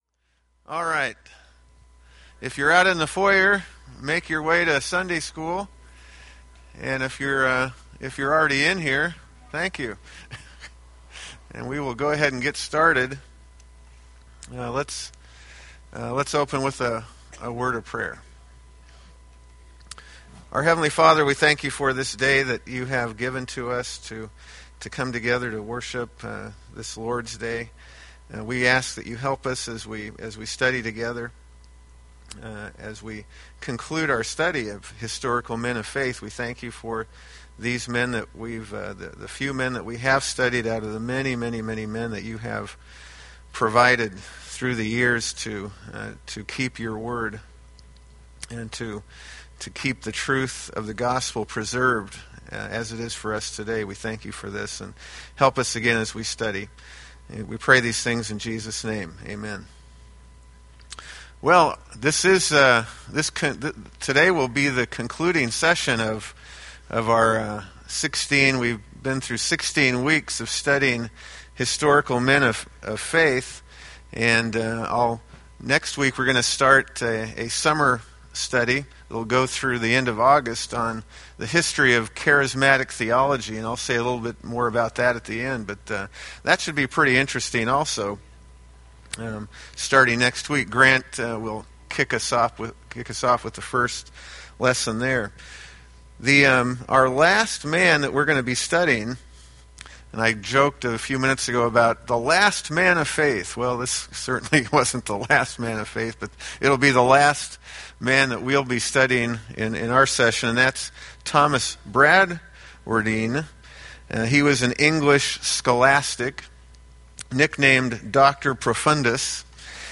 Date: May 24, 2015 Series: Historical Men of Faith Grouping: Sunday School (Adult) More: Download MP3